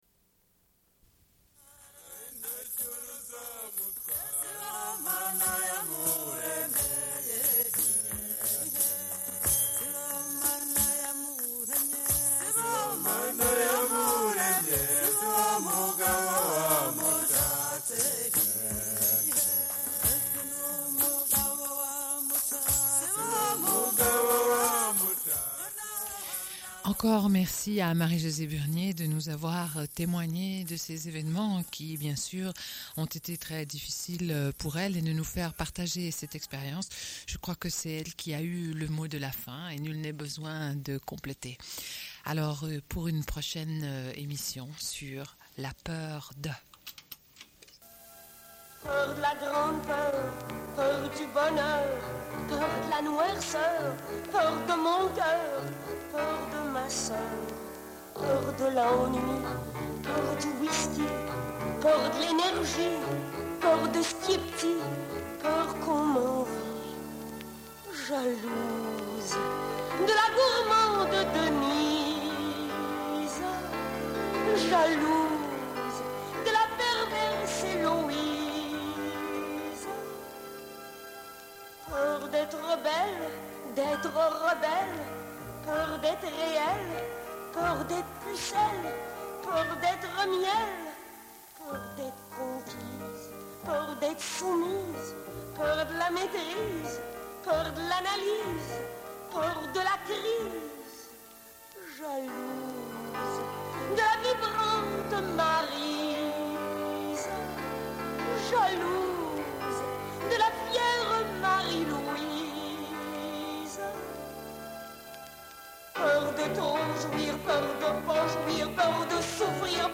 Une cassette audio, face A00:31:53